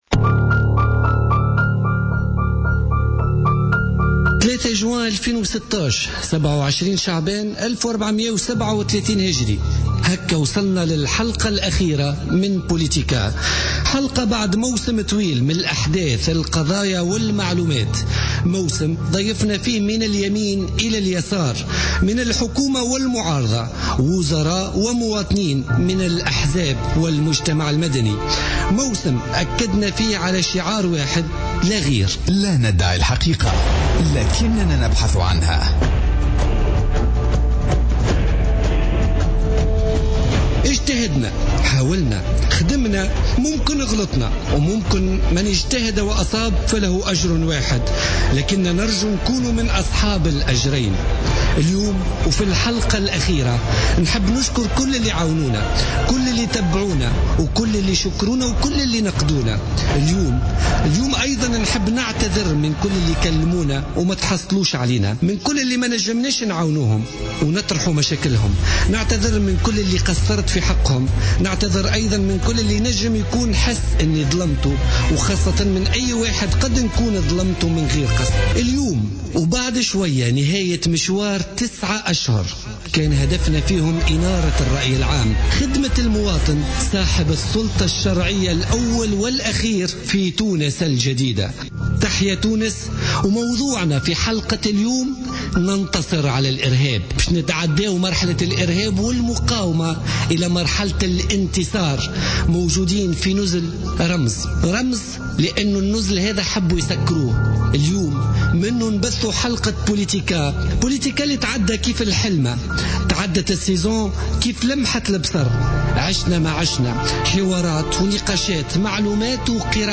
La dernière de la saison 2015-2016 en direct de l'Imperial Marhaba